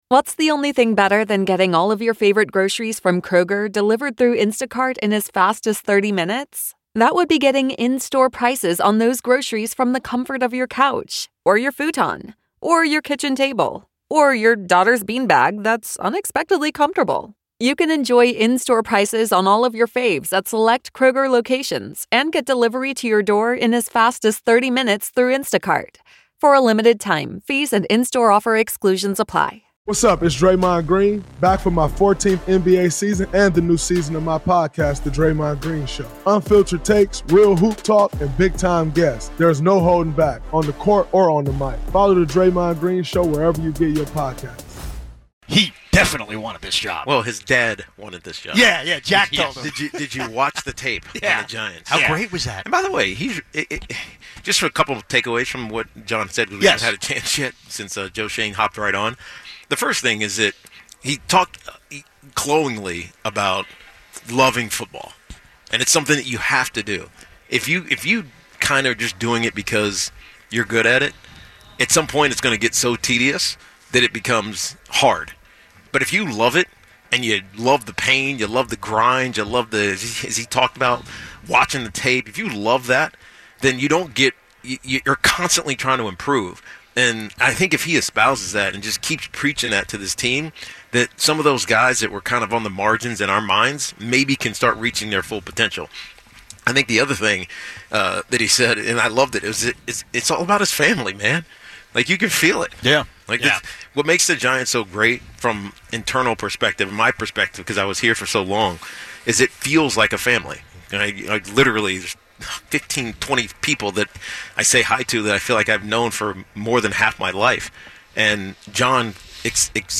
Fan calls pour in with the excitement level through the roof, and the show closes with more reflections on what winning culture actually looks like and what Giants fans should realistically demand next season.